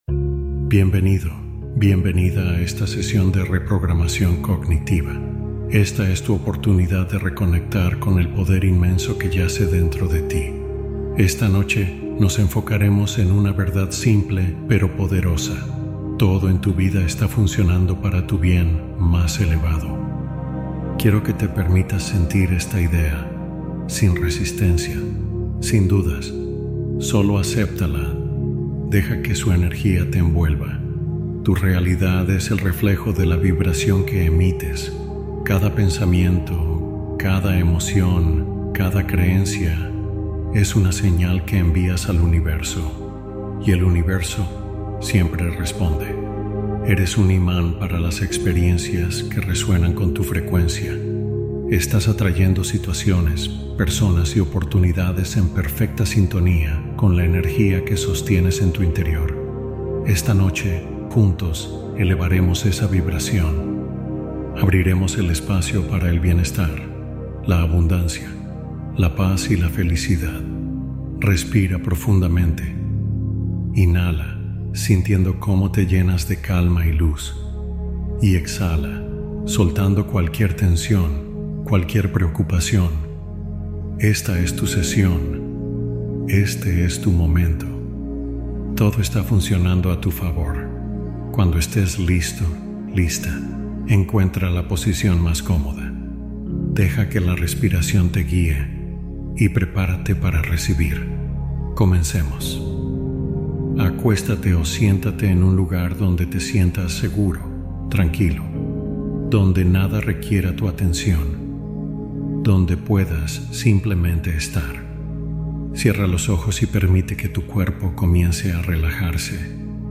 Todo Me Sale Bien | Meditación Nocturna de Reprogramación